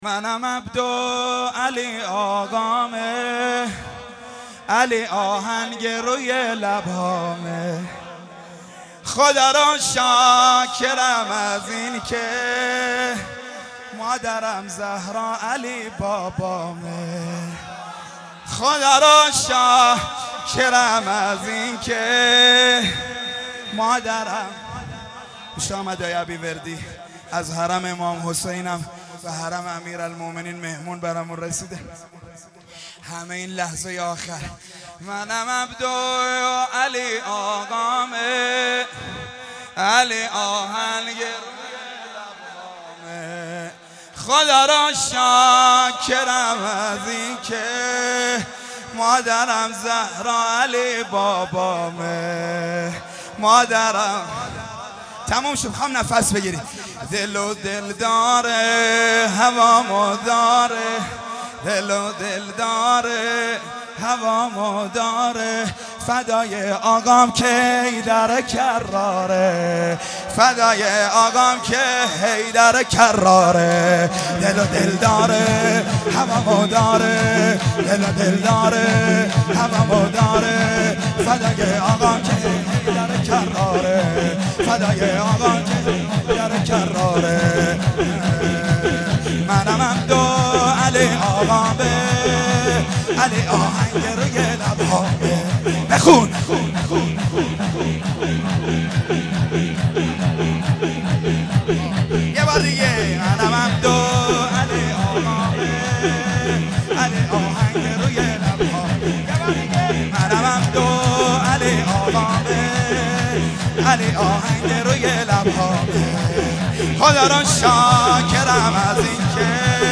سرود: